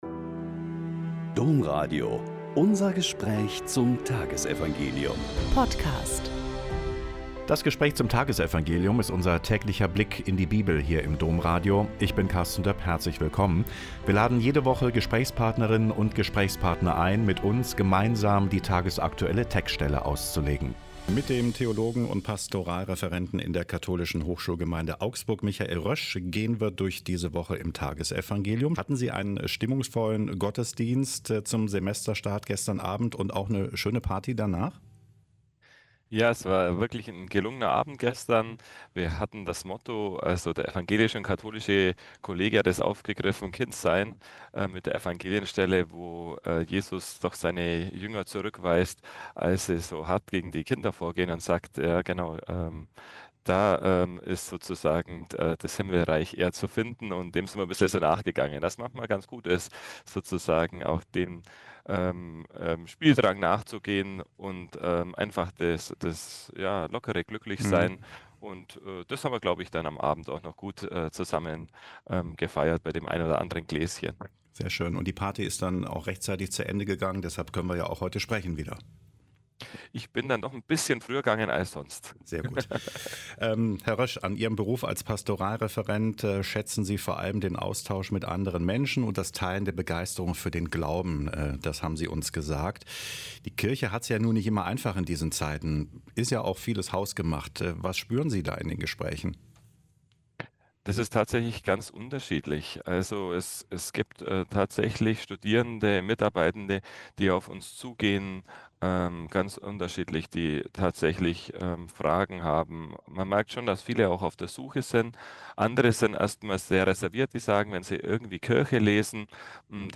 Lk 11,42-46 - Gespräch